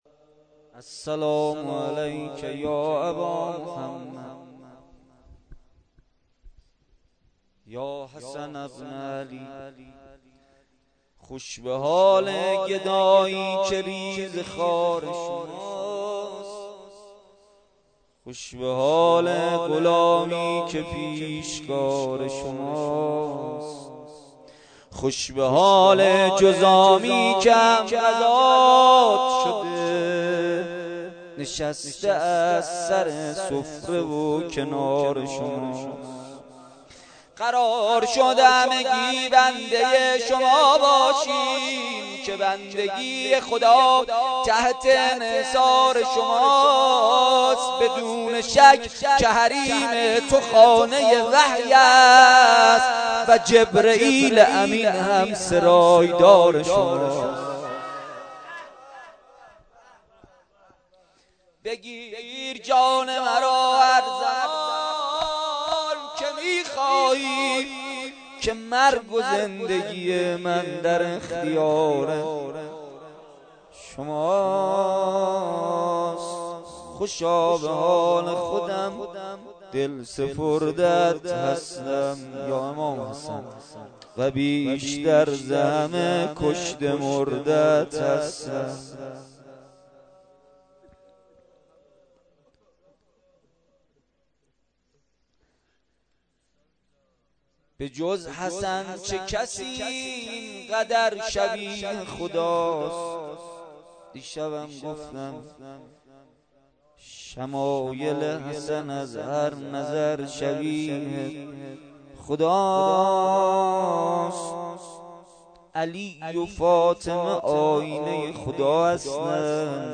شب شانزدهم ماه رمضان با مداحی کربلایی محمدحسین پویانفر در ولنجک – بلوار دانشجو – کهف الشهداء برگزار گردید.
دعا و مناجات روضه لینک کپی شد گزارش خطا پسندها 0 اشتراک گذاری فیسبوک سروش واتس‌اپ لینکدین توییتر تلگرام اشتراک گذاری فیسبوک سروش واتس‌اپ لینکدین توییتر تلگرام